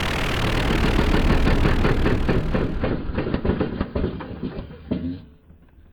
EngineDie.ogg